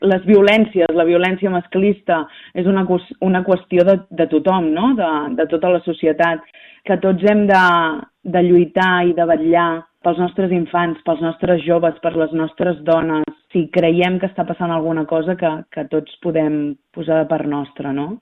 Trencar el silenci i la complicitat social davant les violències masclistes, especialment entre els homes, i implicar-nos tots en la seva denúncia i prevenció és l’objectiu de la commemoració del 25N. Així ho ha assenyalat la regidora d’Igualtat i Drets civils de l’Ajuntament de Calella, Mariceli Santarén, en declaracions a Ràdio Calella TV.